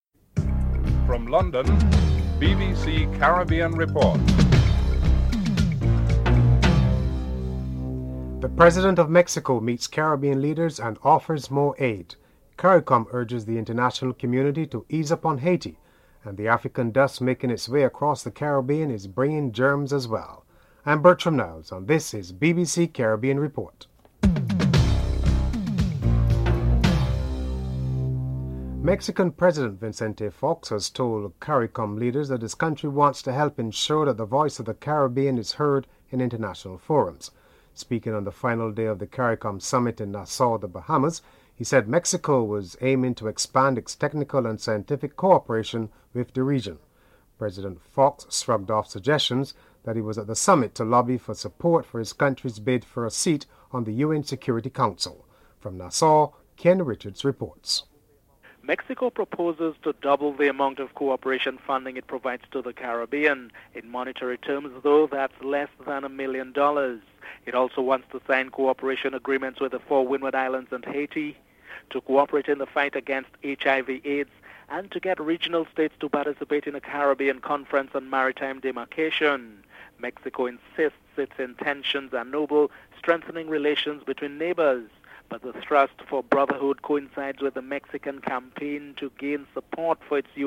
President of Mexico Vicente Fox meets Caribbean leaders and offers more aid. President Vicente Fox and Prime Minister Lester Bird are interviewed.